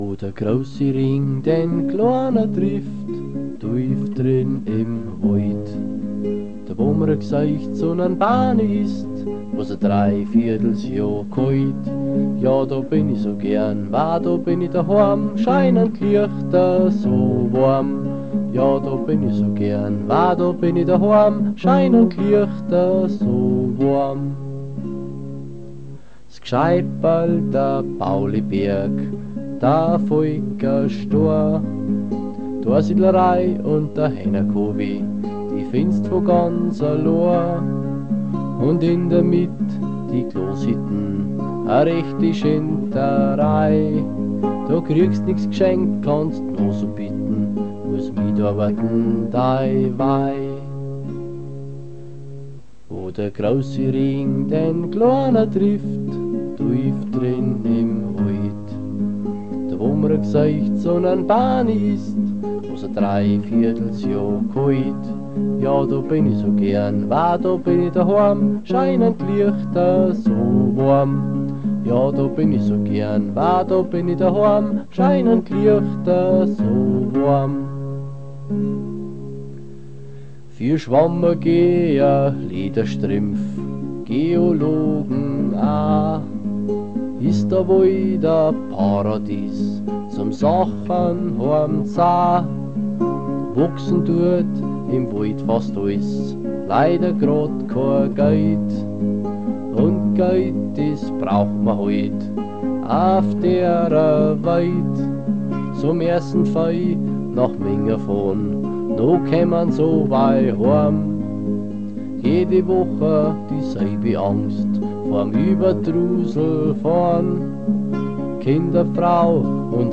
Vozwickte Mundartsongs